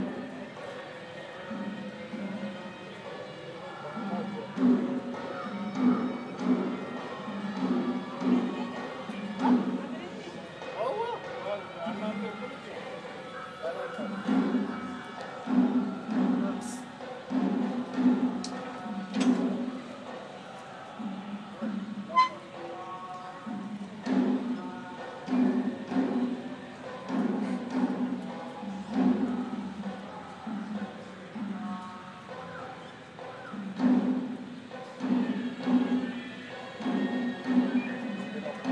Tam tamy przy modlitwie